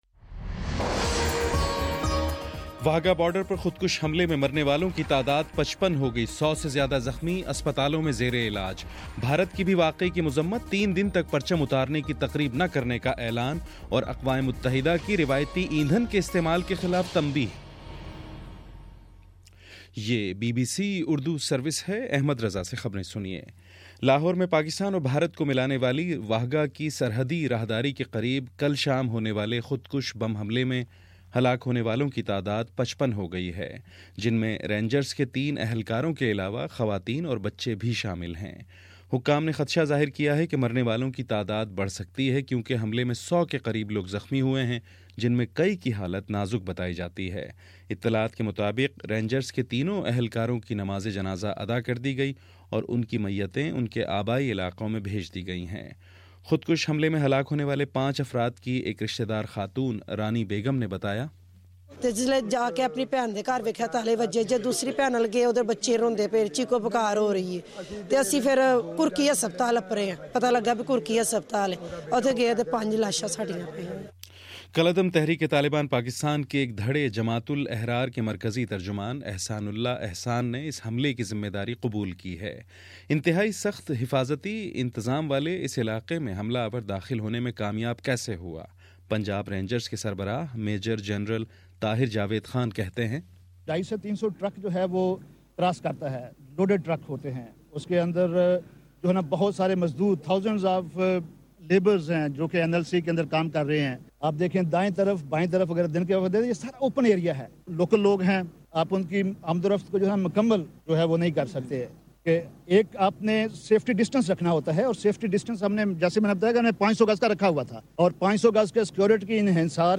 دس منٹ کا نیوز بُلیٹن روزانہ پاکستانی وقت کے مطابق صبح 9 بجے، شام 6 بجے اور پھر 7 بجے۔